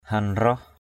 /ha-nrɔh/ 1.